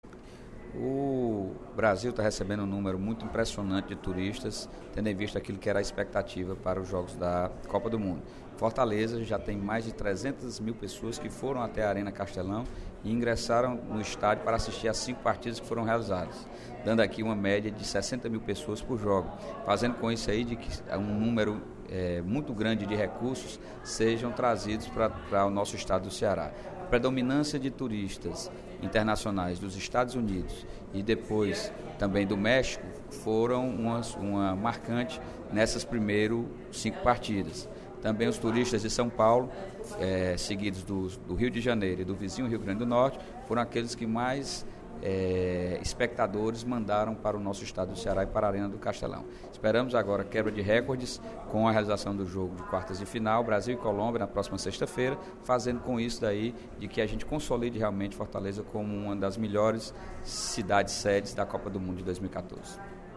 Durante o primeiro expediente da sessão plenária da Assembleia Legislativa desta terça-feira (01/07), o deputado Sérgio Aguiar (Pros) destacou que a Copa do Mundo Fifa 2014 possui números convincentes para indicar a movimentação econômica que está realizando.